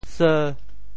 sêu têung in Teochew), which functions as the “memory bank” of the village.
seu5.mp3